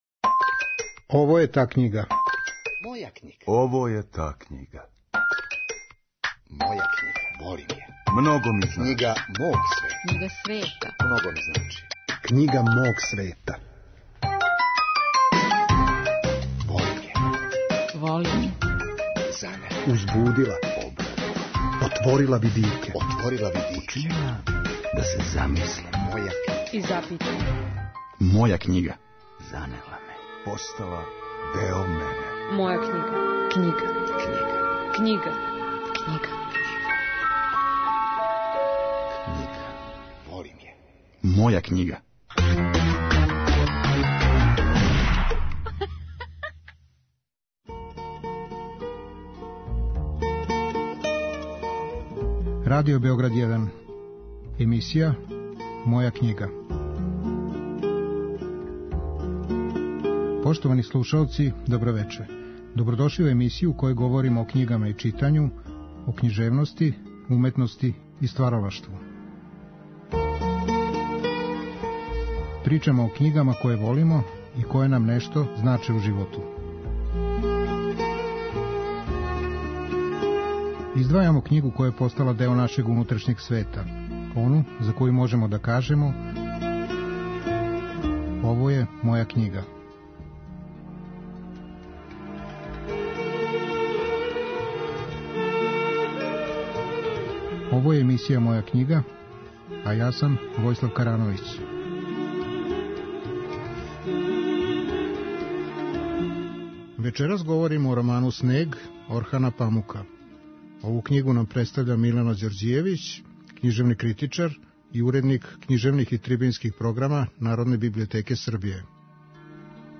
Наша саговорница говори о својим читалачким почецима, о књигама која су битно утицале на њено формирање, о томе како је открила роман ''Снег'' Орхана Памука. Пошто се бавила темом сукоба Истока и Запада у књижевним делима неких писаца, наша гошћа ће нам рећи нешто и о томе на који начин је овај сукоб присутан у делу Орхана Памука.